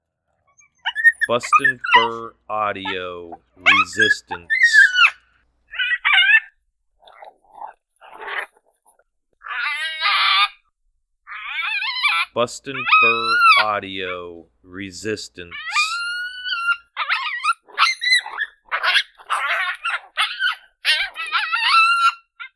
Juvenile Male Coyote distress.